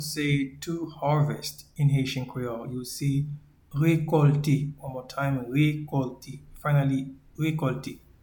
Pronunciation and Transcript:
to-Harvest-in-Haitian-Creole-Rekolte.mp3